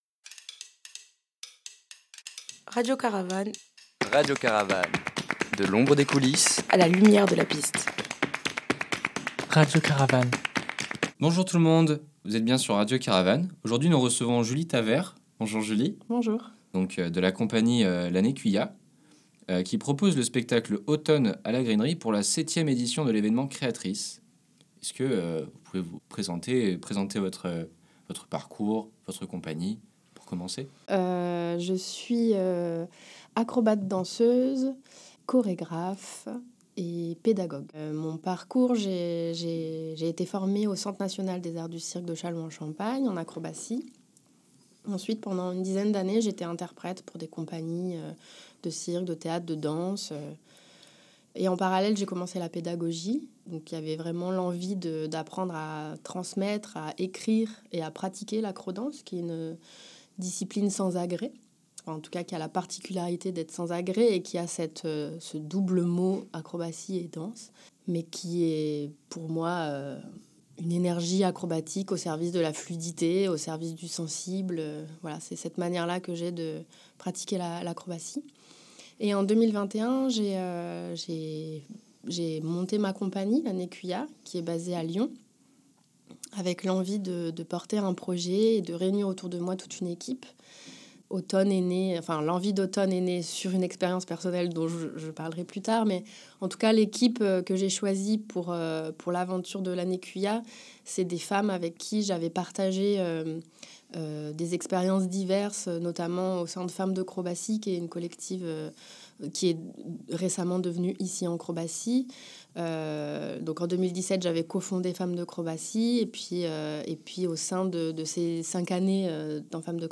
Ecoutez cette interview en toute sensibilité et émotions.